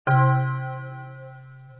church bell
bell.mp3